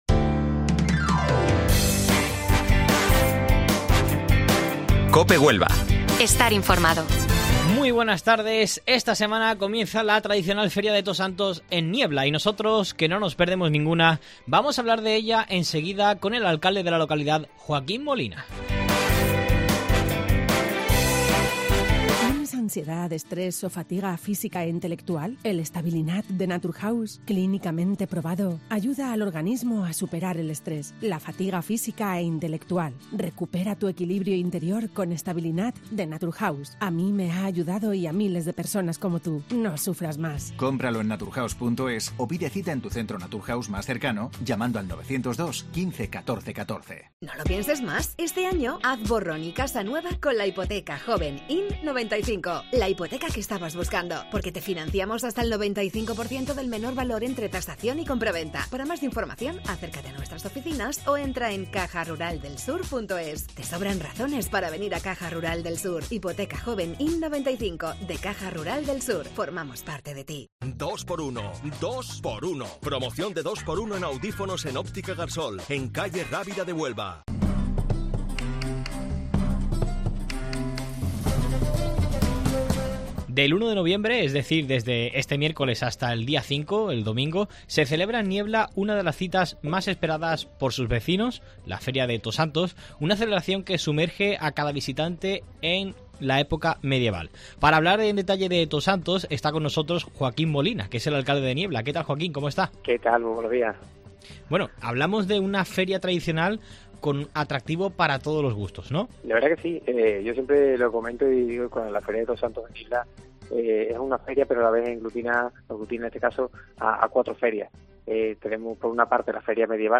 Joaquín Molina, alcalde de Niebla, sobre la Feria de Tosantos